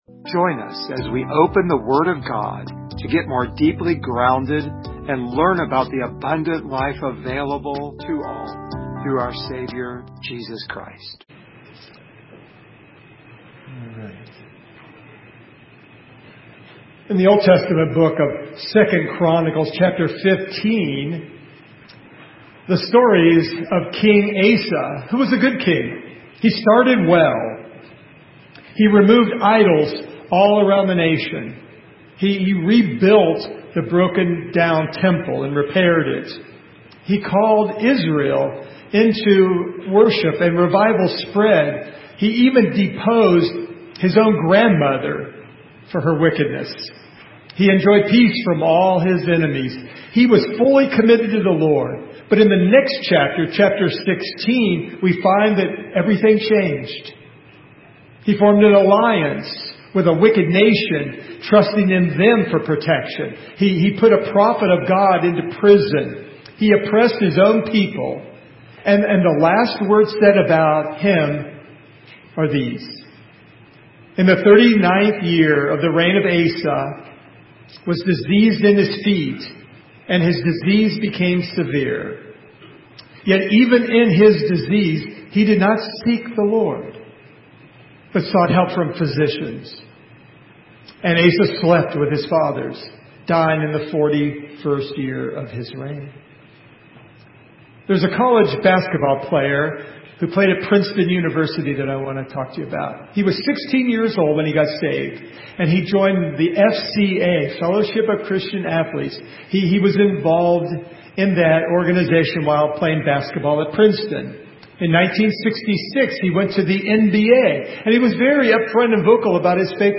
Passage: John 6:60-71 Service Type: Sunday Morning